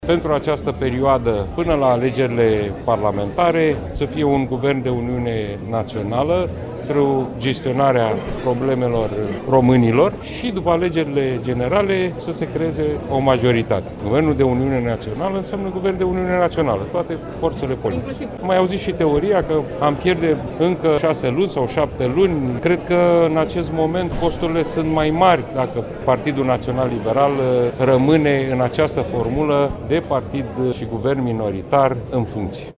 Preşedintele interimar al partidului, Marcel Ciolacu, consideră că România are nevoie de un guvern de uniune naţională din care să facă parte toate partidele parlamentare:
stiri-19-ian-Ciolacu.mp3